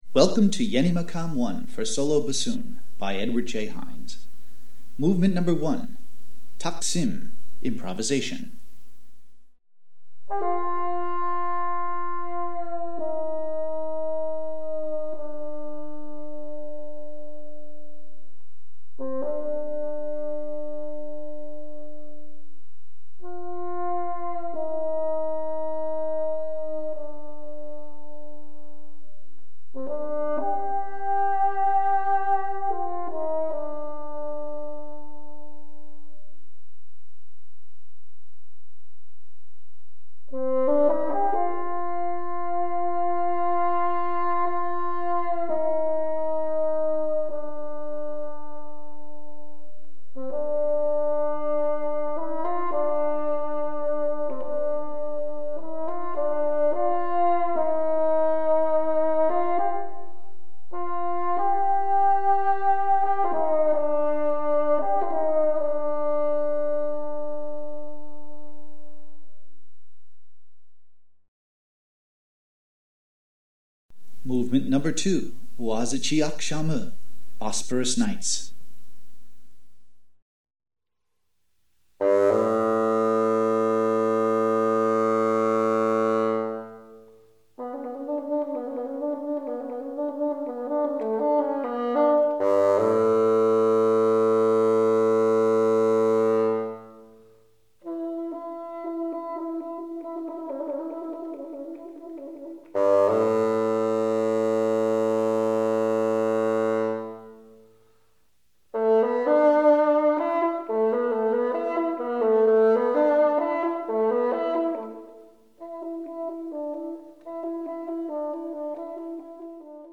brings a world music sound to the concert hall